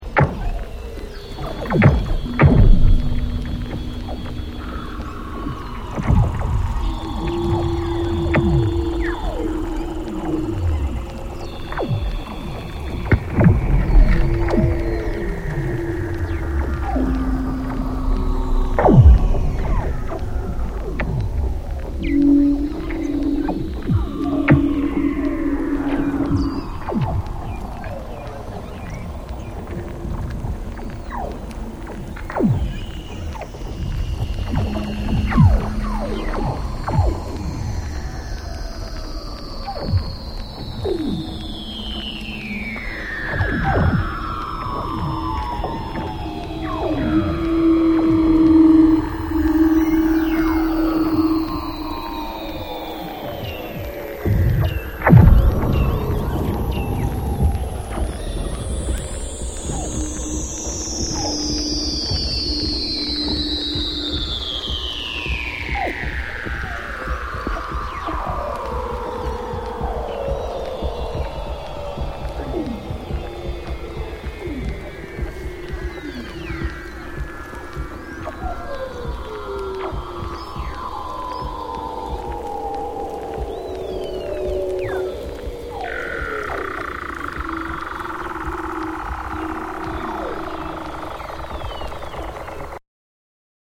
主にフィールドレコーディング音源を用いた秀逸なミックスCD!!